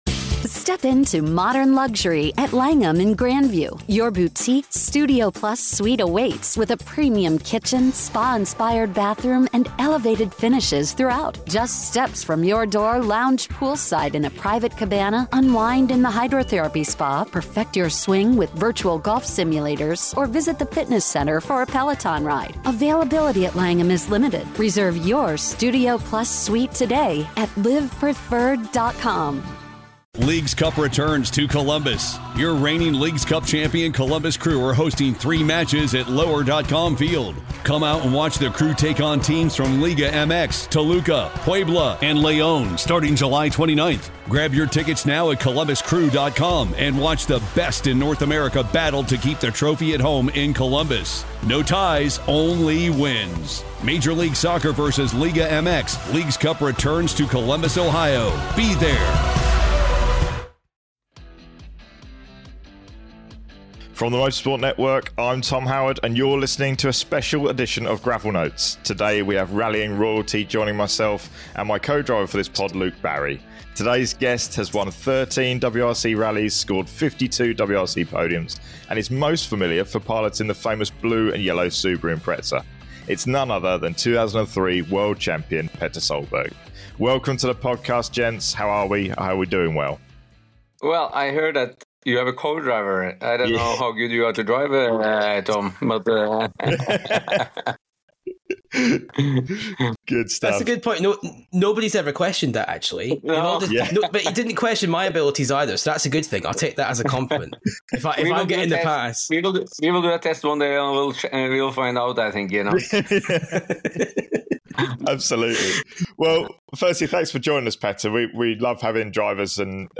Interview: World Champion Petter Solberg